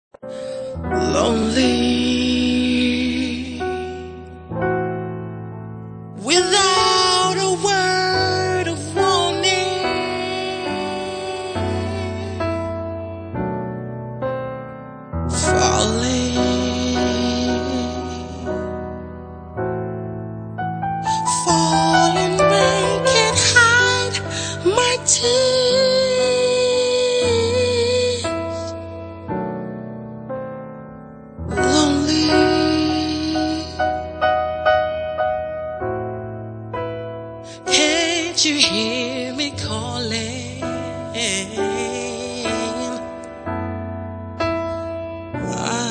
Soul/R&B/Jazz